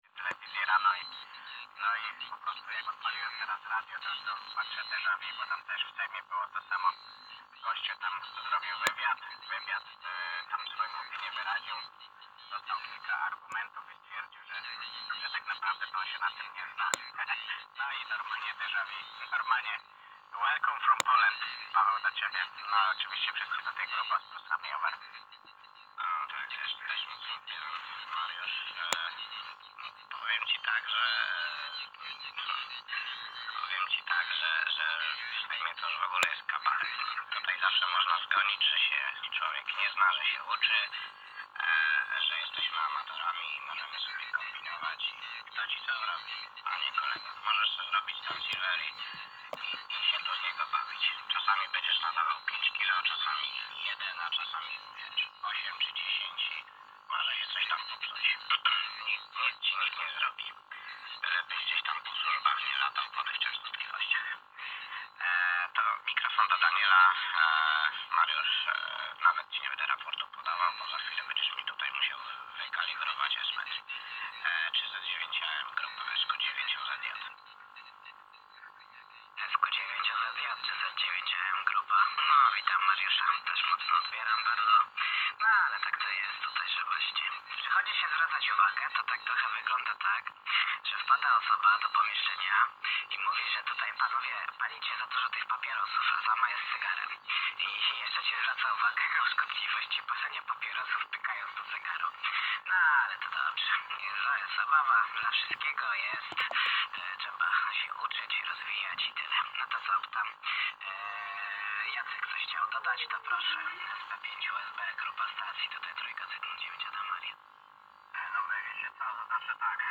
Żeby nie być gołosłownym, zamieszczam również krótkie nagrania, ale uprzedzam, że są one zrealizowane za pomocą telefonu przyłożonego do jednej ze słuchawek, w dodatku spletteruje bardzo mocna, nadająca blisko stacja: